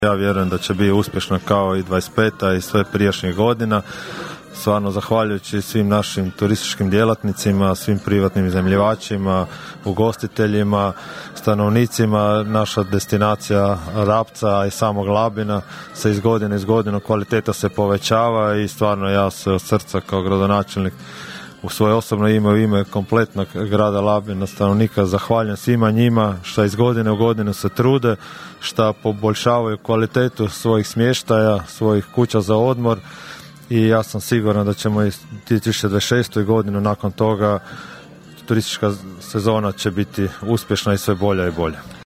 Predsjednik Skupštine, labinski gradonačelnik Donald Blašković o očekivanjima od turističke 2026. godine: (